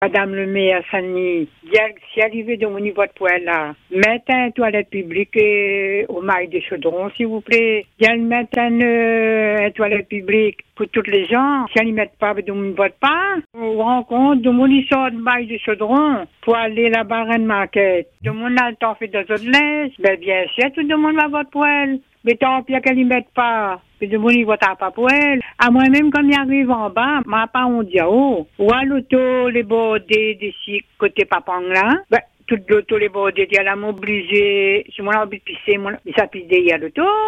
Une habitante du quartier du Chaudron souhaite alerter sur une situation du quotidien qui devient problématique pour de nombreux usagers : l’absence de toilettes publiques au point de gare.